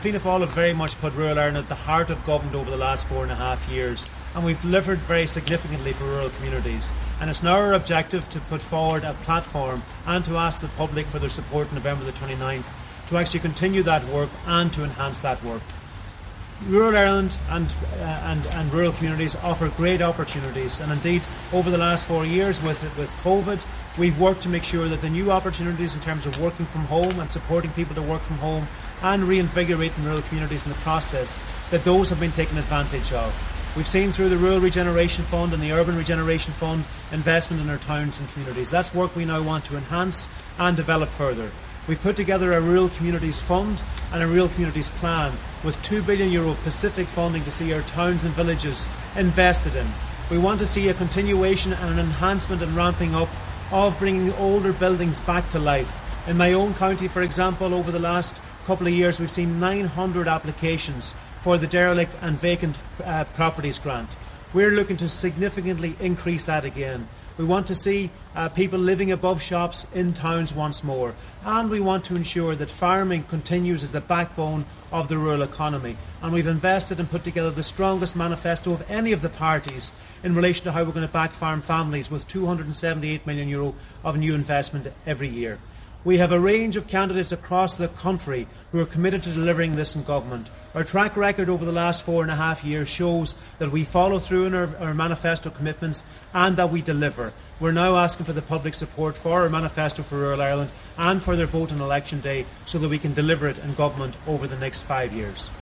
He says it’s about breathing life into rural areas: